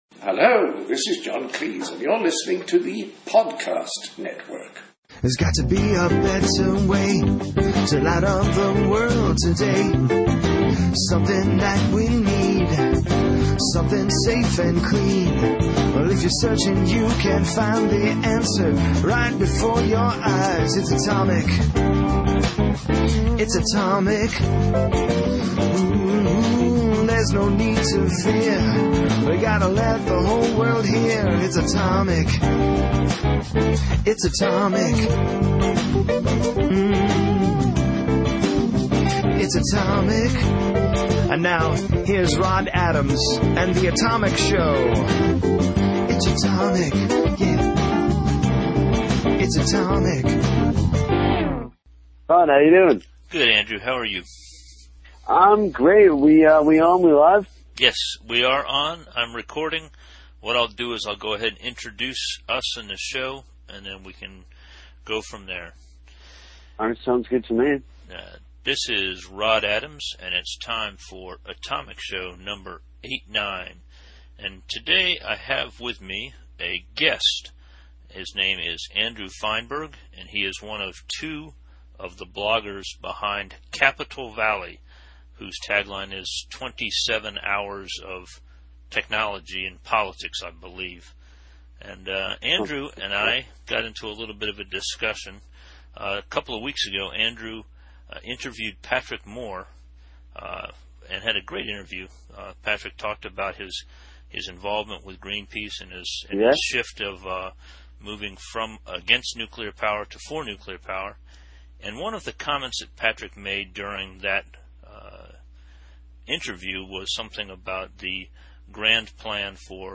It is fun to share thoughts about history with someone just about half your age. Our personal experience lenses are quite different; the mixed points of view result in a good conversation.